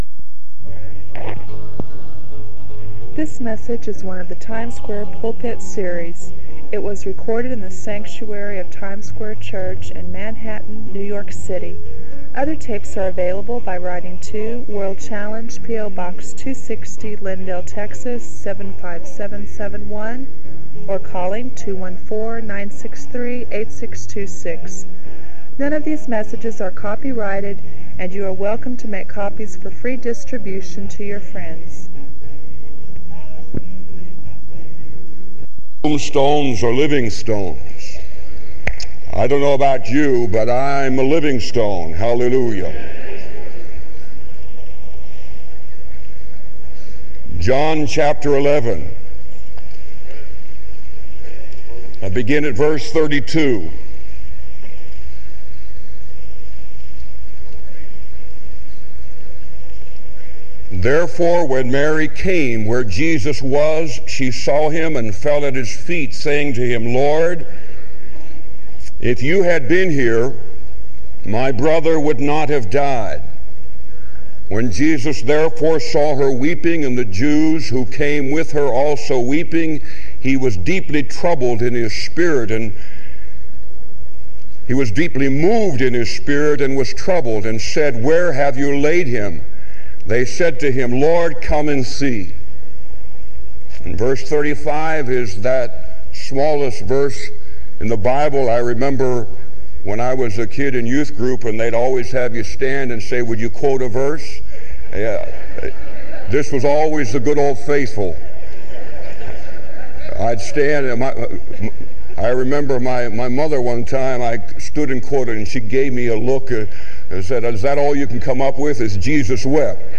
This sermon offers hope and practical encouragement for those struggling with doubt, fear, and waiting on God's timing.